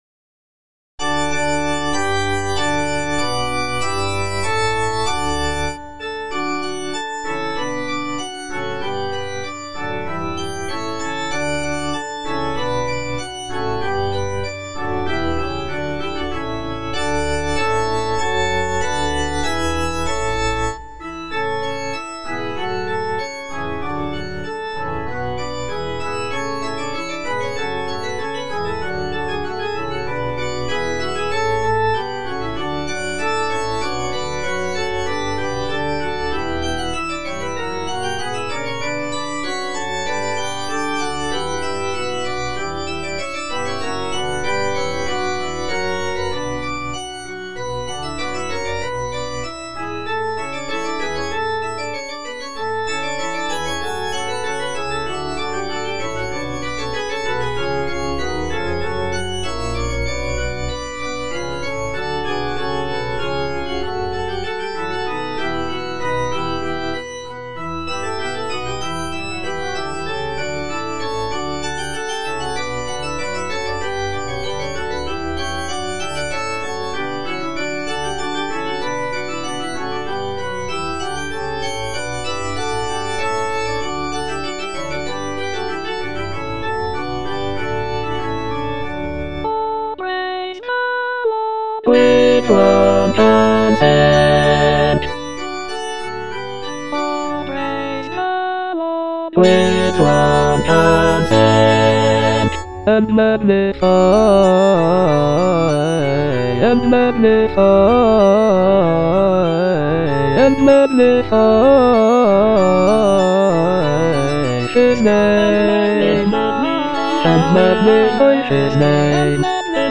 (A = 415 Hz)
Bass (Emphasised voice and other voices) Ads stop